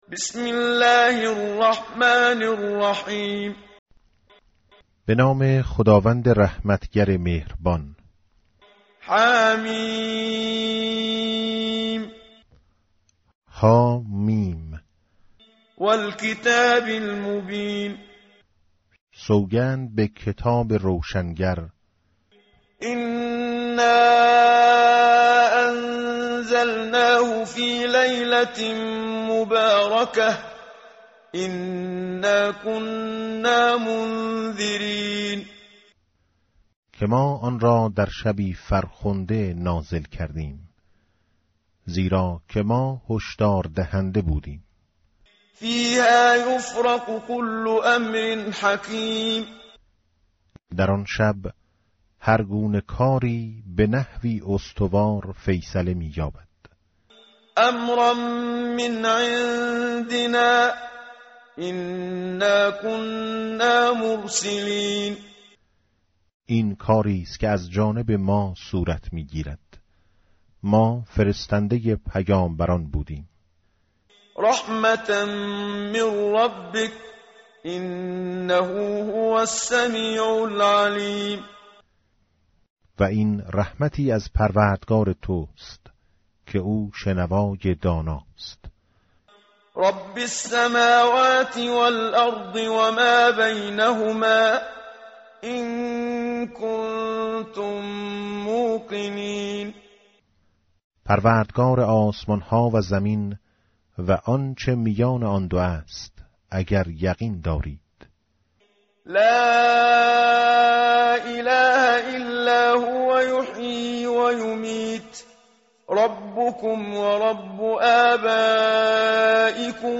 متن قرآن همراه باتلاوت قرآن و ترجمه
tartil_menshavi va tarjome_Page_496.mp3